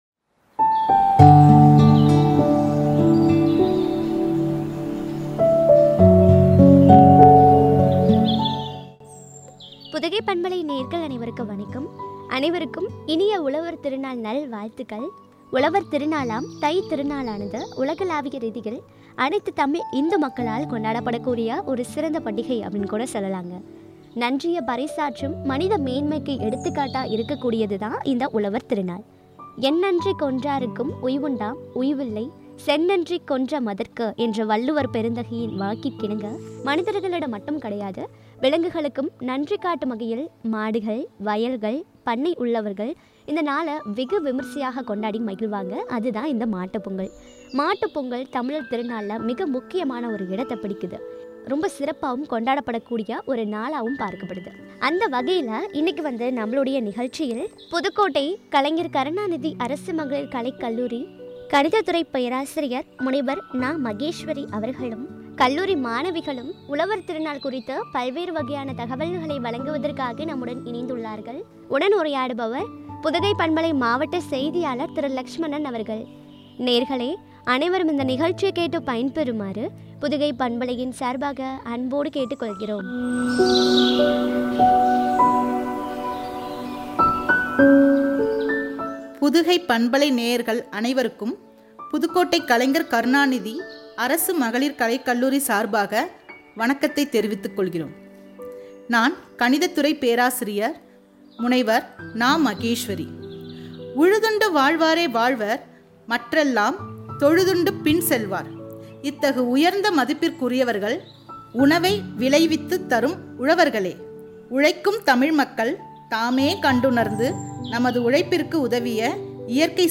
உலகிற்கு உணர்த்துவோம்” குறித்து வழங்கிய உரையாடல்.